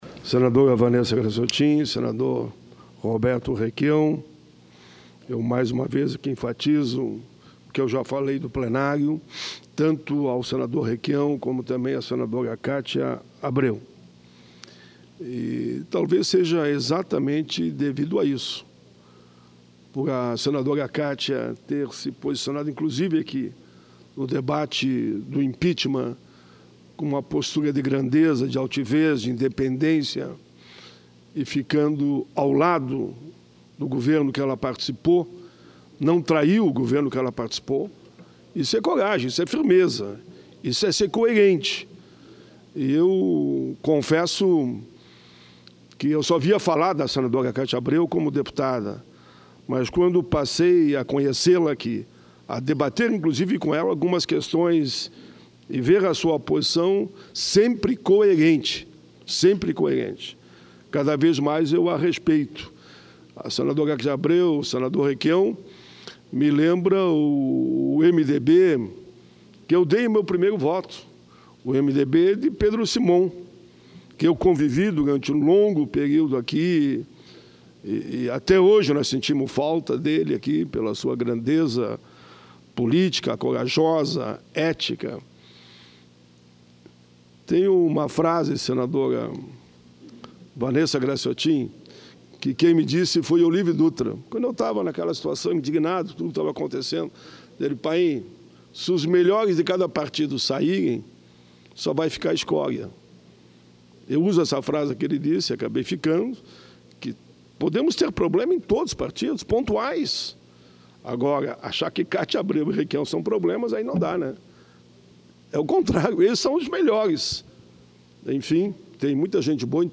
Discursos
Pronunciamento